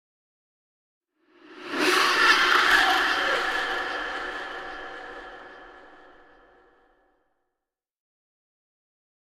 На этой странице собраны звуки безумия: маниакальный смех, невнятные крики, стоны и другие проявления психоза.
Жуткий звук женского смеха